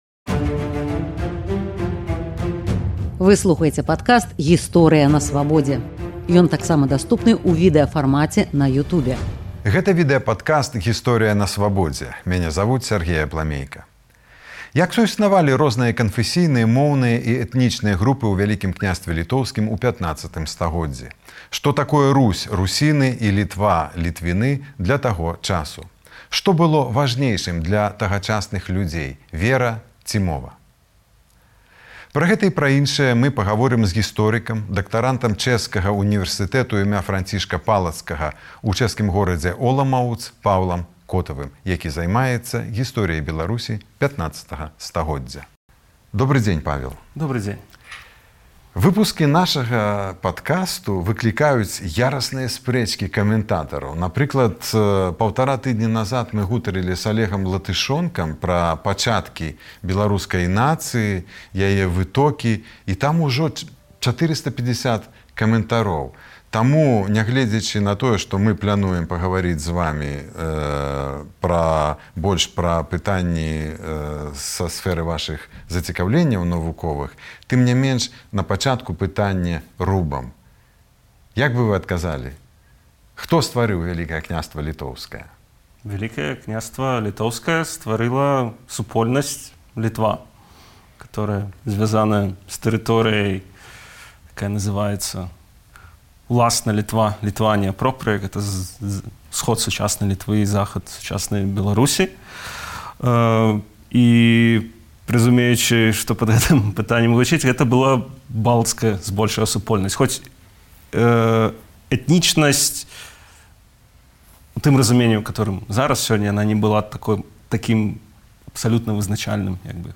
Гаворым з гісторыкам